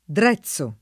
[ dr $ZZ o ]